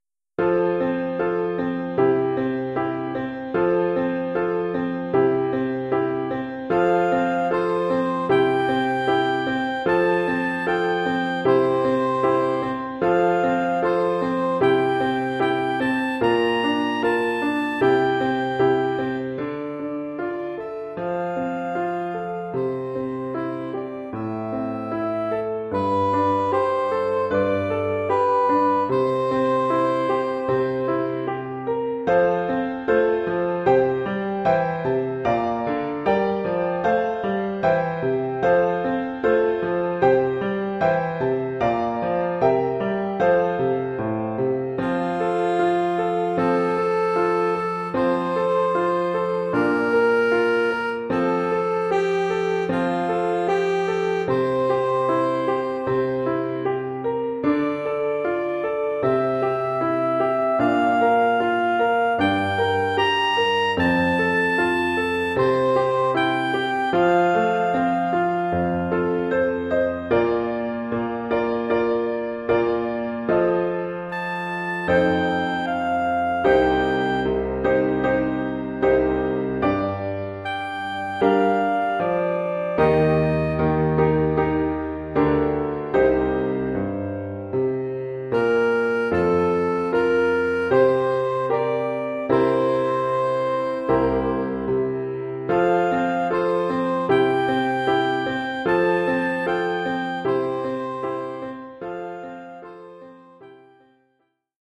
Oeuvre pour saxophone sib et piano.
Niveau : débutant (1er cycle, 1ère année).